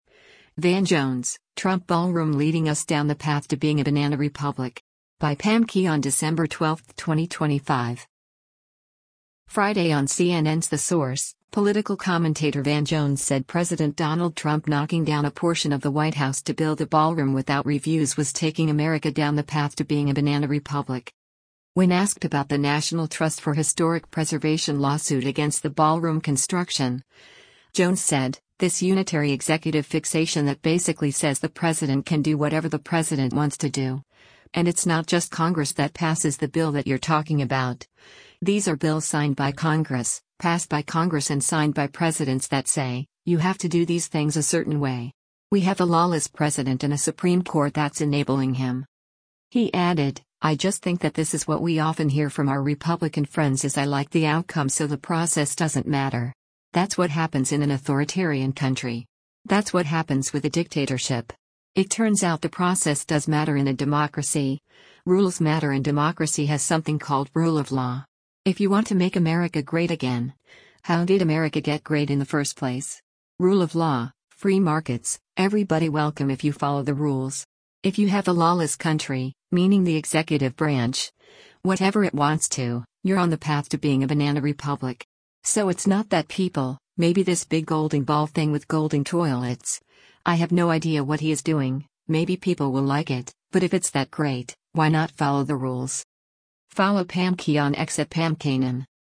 Friday on CNN’s “The Source,” political commentator Van Jones said President Donald Trump knocking down a portion of the White House to build a ballroom without reviews was taking America down the path to “being a banana republic.”